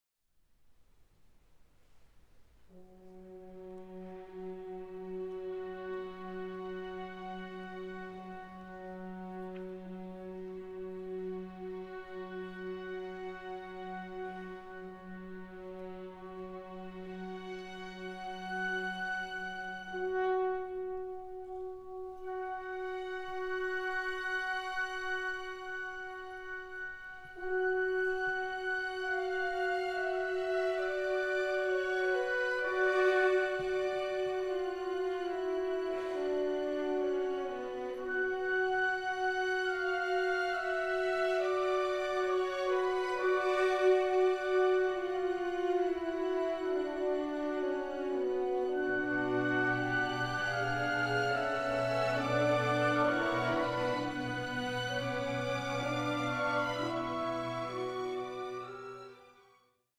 for soloists, choir and orchestra
Live recording